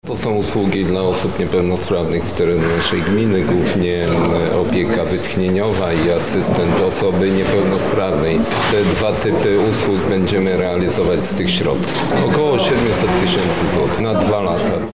O jednej z inicjatyw mówi Jacek Anasiewicz, wójt gminy Głusk: